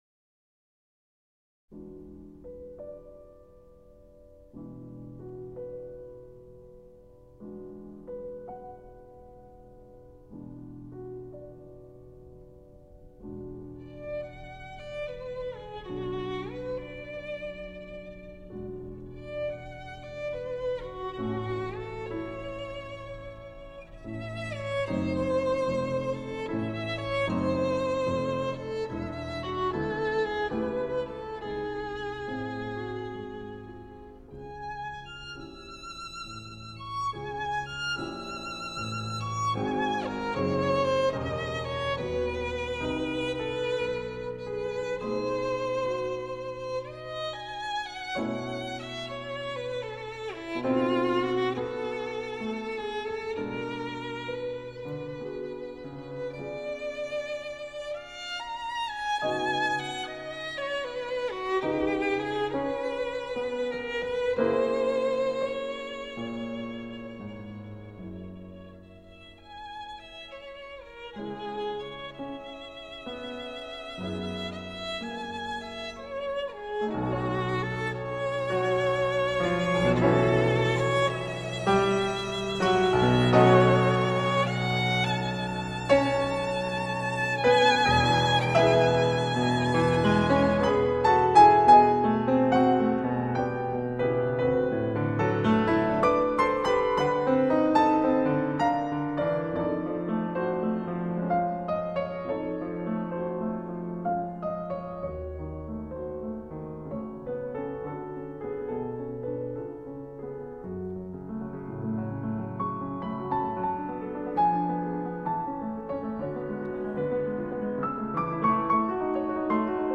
钢琴
出色的演奏和音质使此片被企鹅评为三星带花。